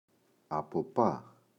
αποπά [apo’pa]